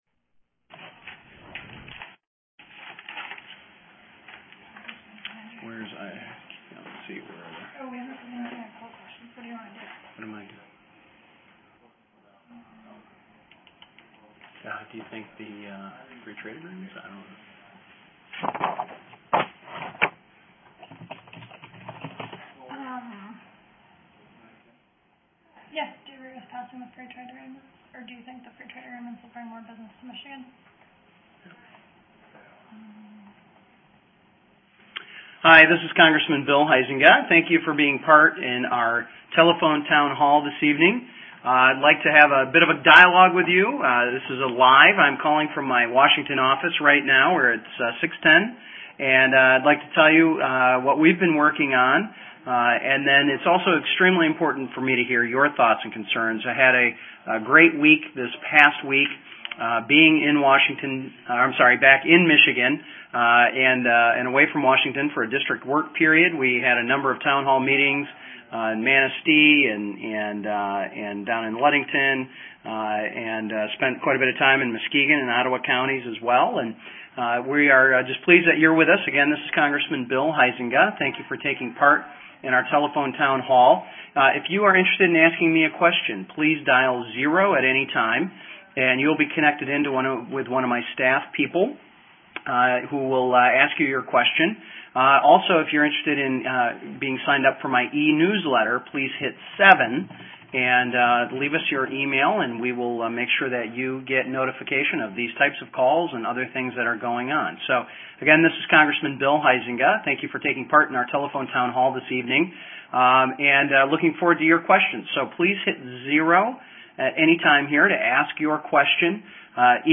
LISTEN: Huizenga Hosts Teletown Hall With 6,000 Constituents
Huizenga_Teletown_Hall_10-25.mp3